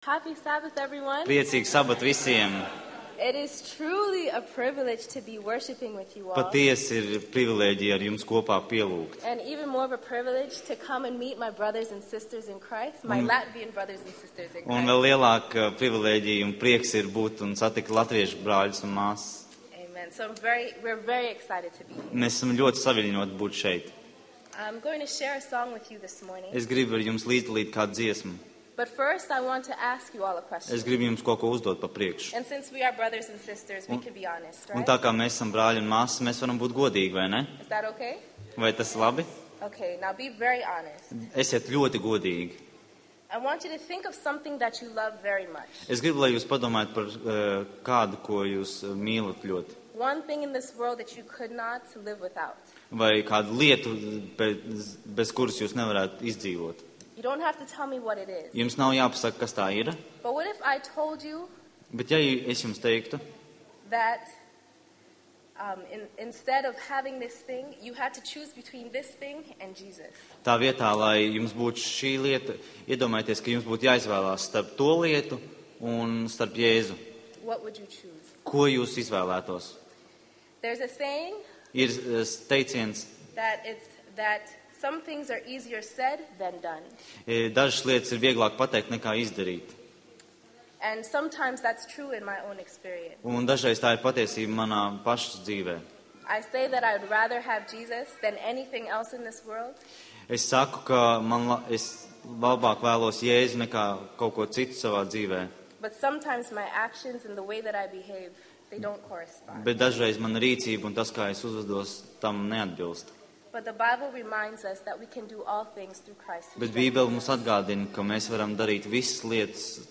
Seminārs